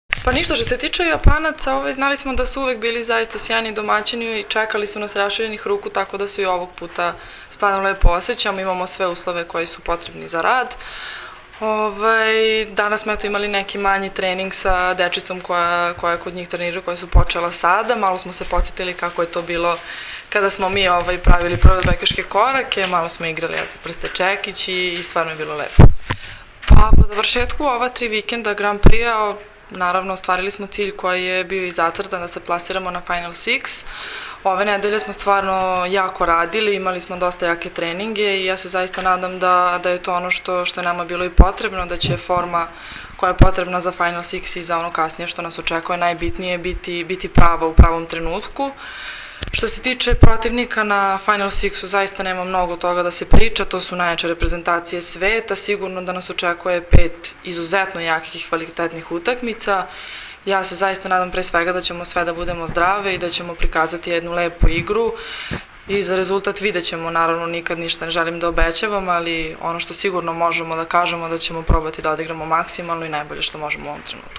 IZJAVA SANJE MALAGURSKI